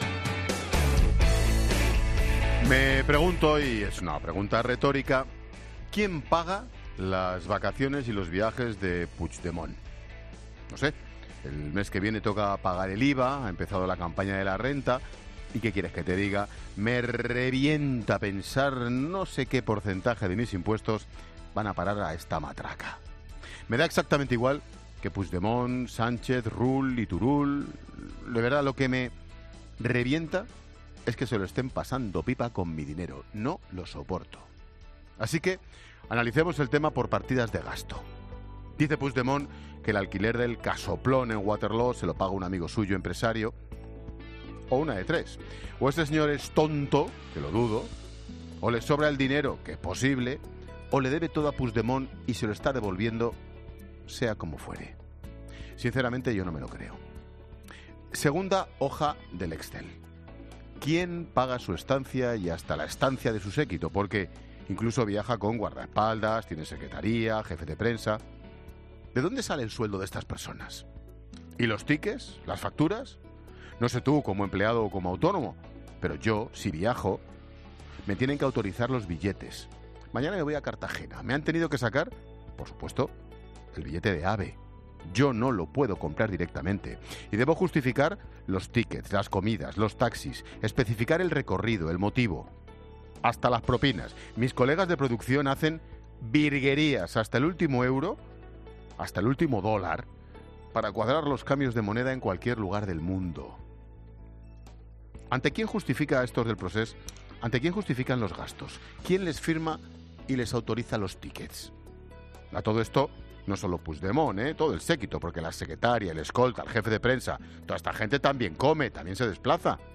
Monólogo de Expósito
El comentario de Ángel Expósito sobre los gastos de Puigdemont en el extranjero.